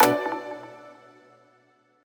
menu-exit-click.ogg